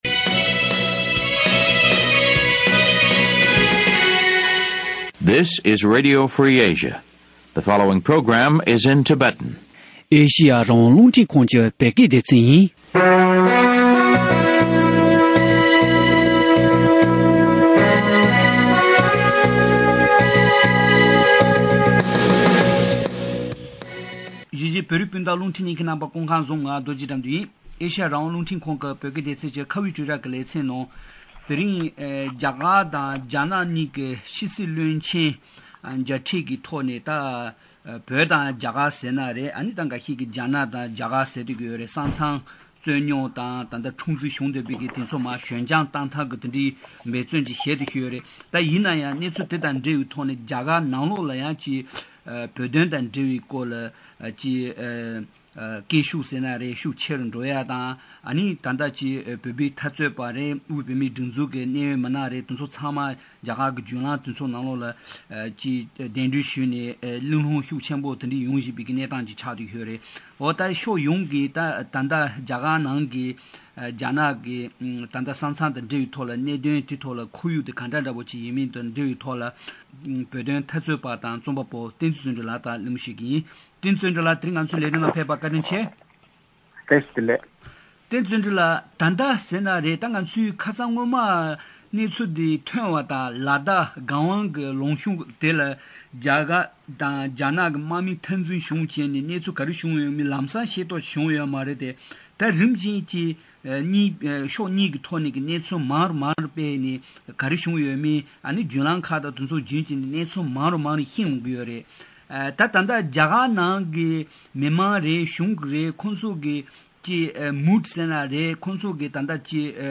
བཀའ་མོལ་ཞུས་པར་གསན་རོགས་ཞུ།།